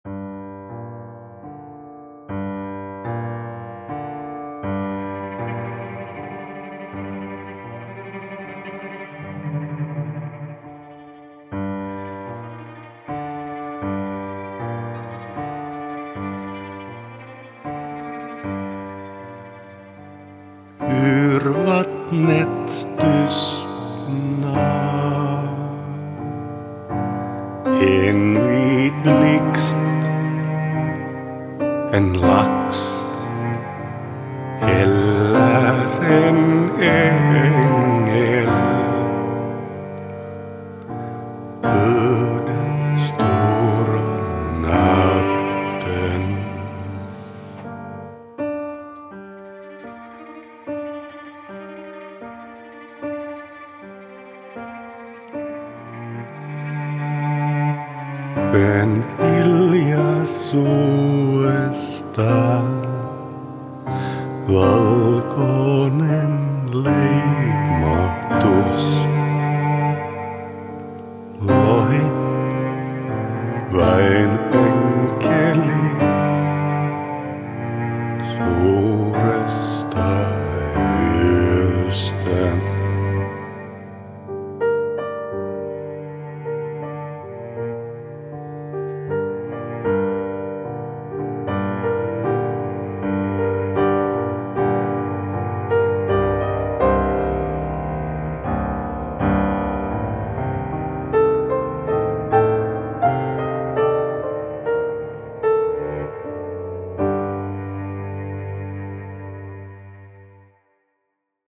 Noissa minun äänitteissä minä soitan joko puhallinohjaimella tai koskettimilla. Äänen tekee digitaalitekniikka.